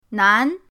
nan2.mp3